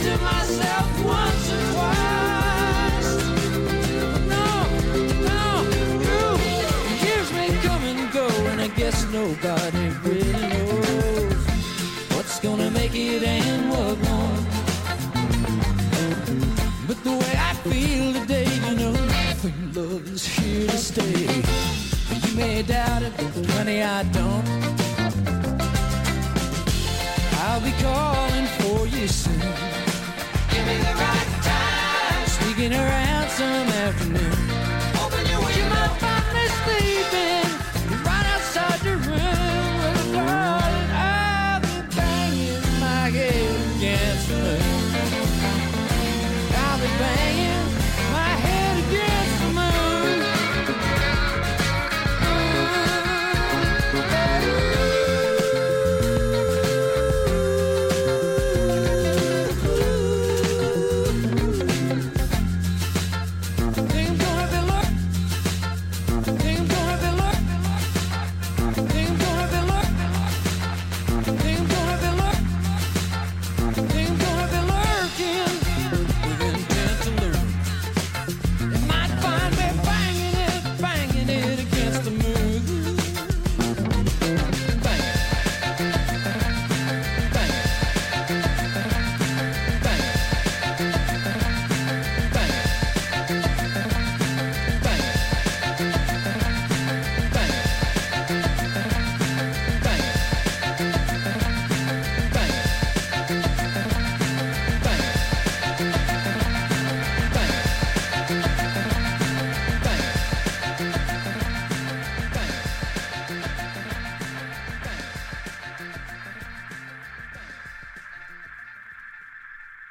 Balearic
edits
a nice breezy vocal